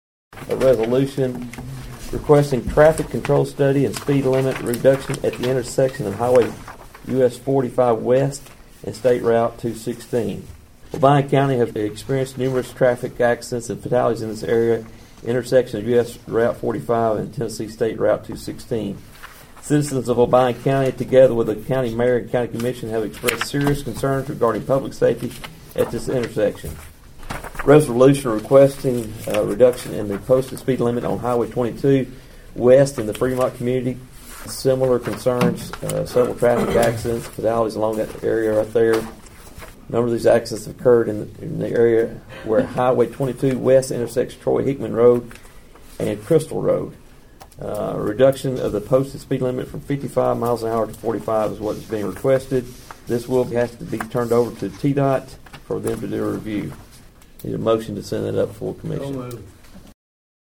On Monday, Budget Committee Chairman Sam Sinclair Jr. read Resolutions seeking help from the Tennessee Department of Transportation.(AUDIO)